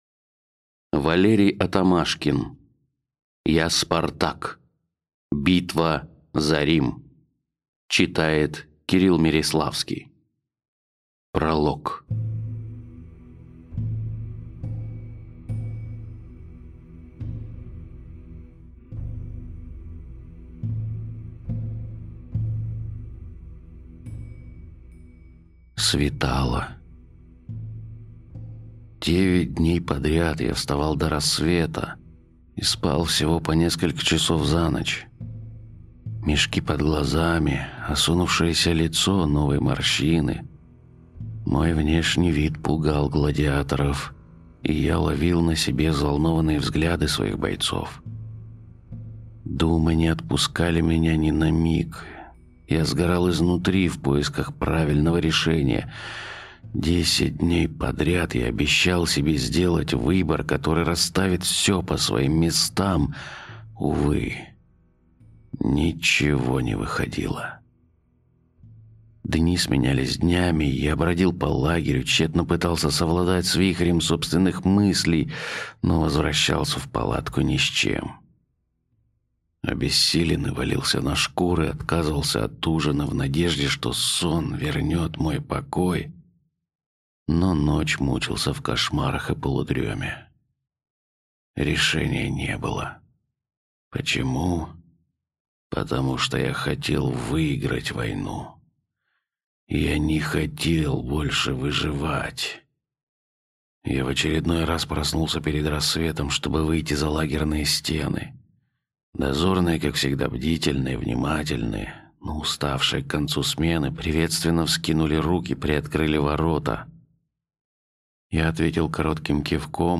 Аудиокнига Я – Спартак! Битва за Рим | Библиотека аудиокниг